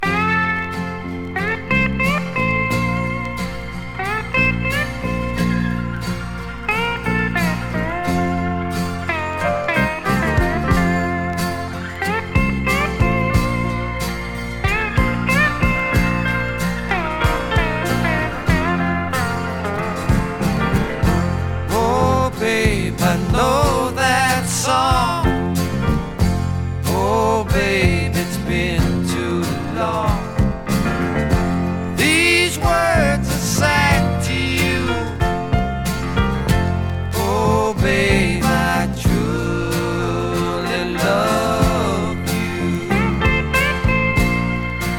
SideAでは英国の香りが漂い、ドラマチックさたっぷり。SideBはポップさとスワンプ感も。
Rock, Pop　USA　12inchレコード　33rpm　Stereo